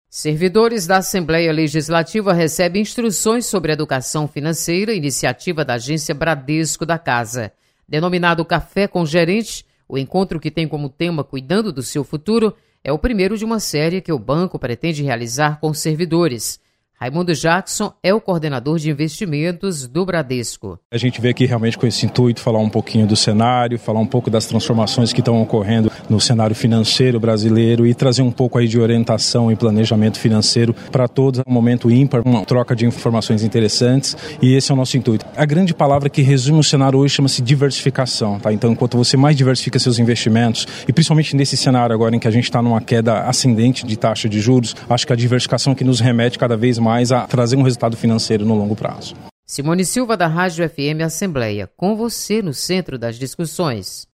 Banco Bradesco presta serviço de educação financeira aos servidores da Assembleia Legislativa. Repórter